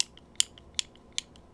R - Foley 263.wav